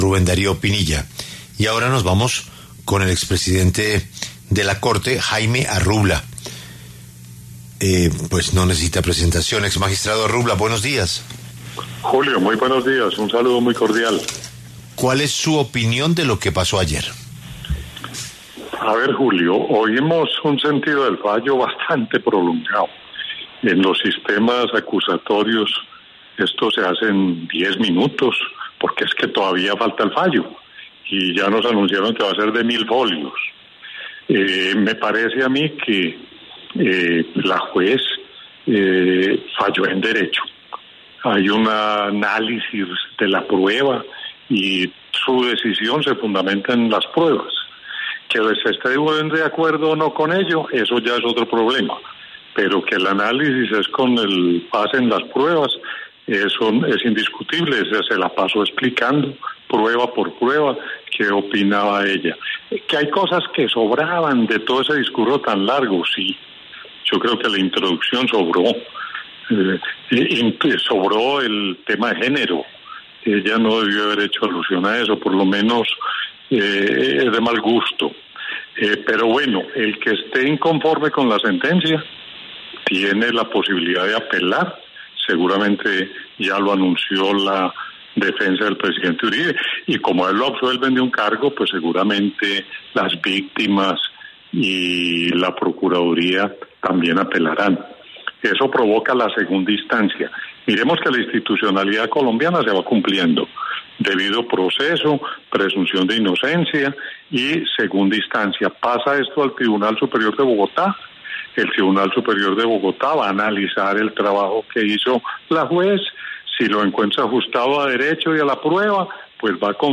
Al respecto, el exmiagistrado Jaime Arrubla habló en La W este martes, 29 de julio, sobre la coyuntura y defendió la decisión tomada por la juez Sandra Liliana Heredia.